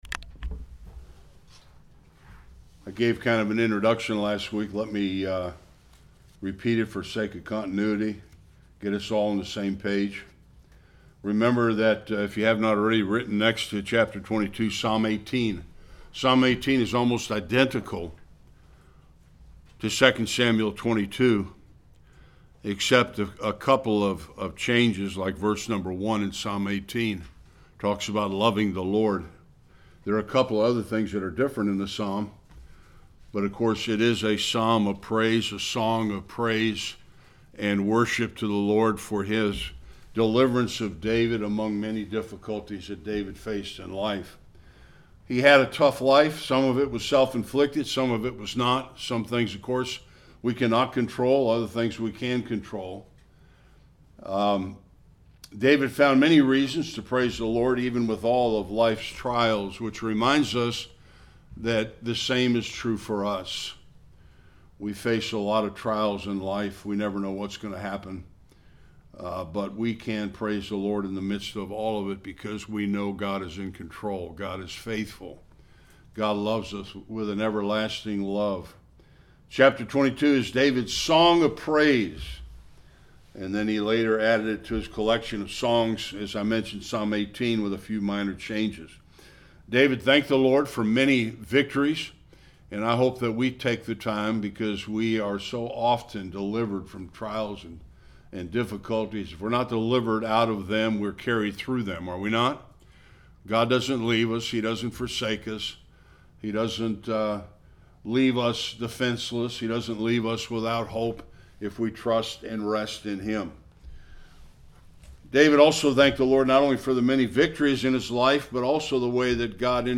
1-28 Service Type: Sunday School King David praises God for the victories he enjoyed.